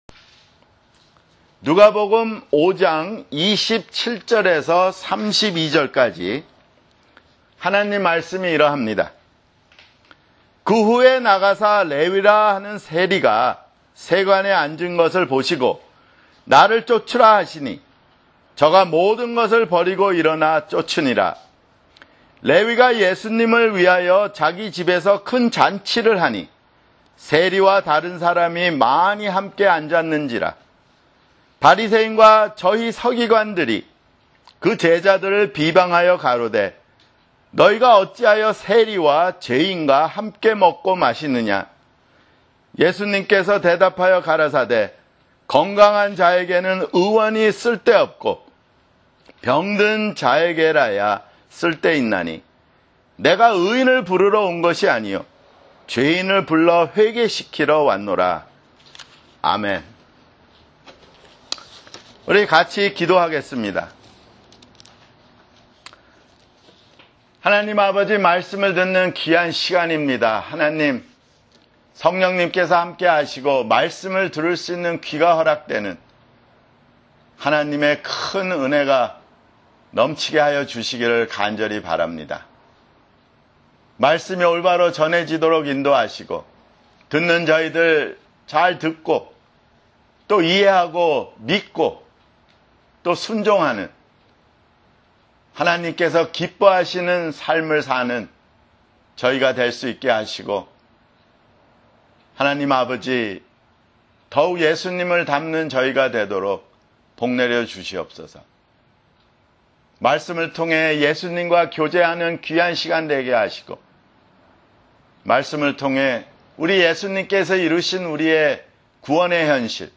[주일설교] 누가복음 (35)